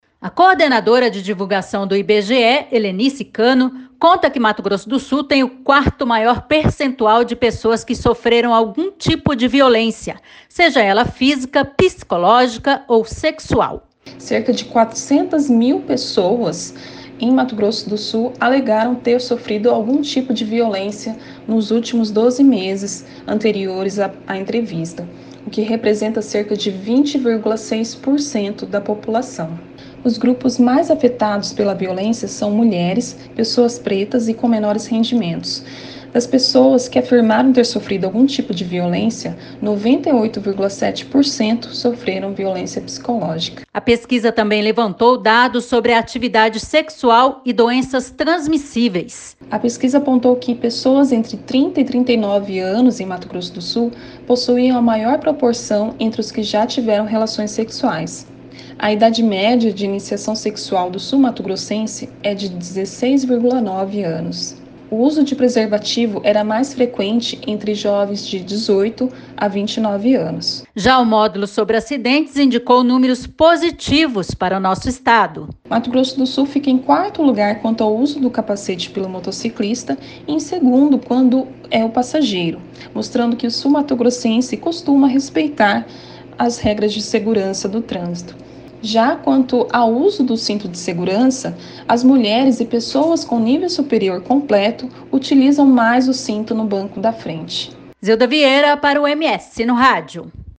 Confira as informações com a repórter